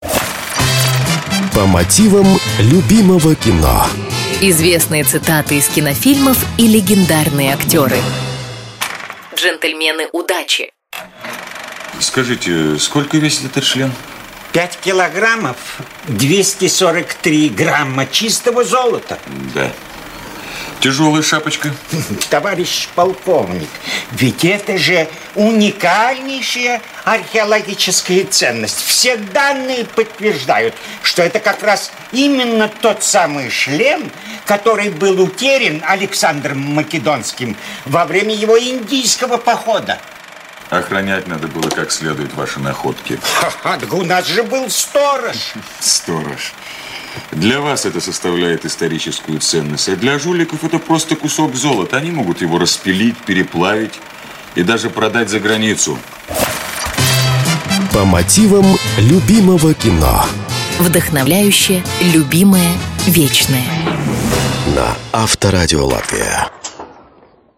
В этой программе вы услышите знаменитые цитаты из кинофильмов, озвученные голосами легендарных актеров.